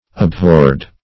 Abhorred - definition of Abhorred - synonyms, pronunciation, spelling from Free Dictionary